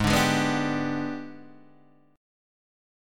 G#mM7b5 chord